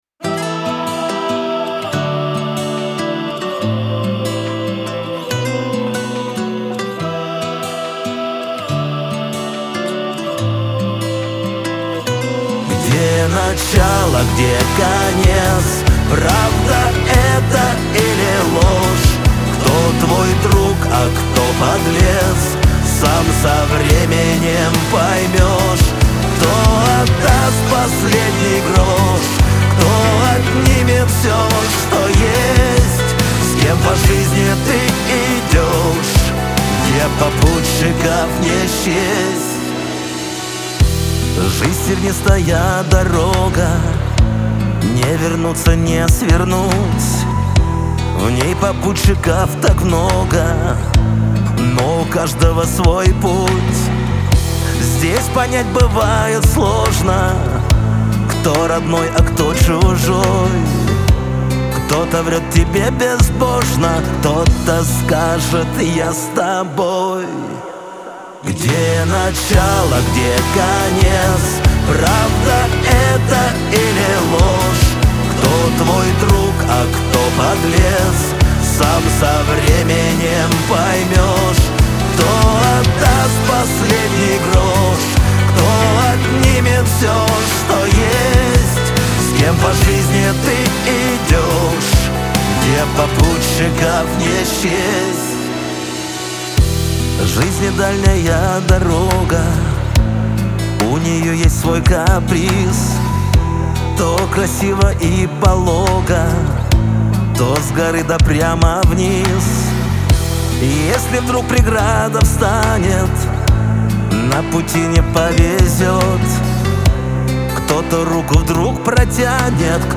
pop
эстрада
Лирика , грусть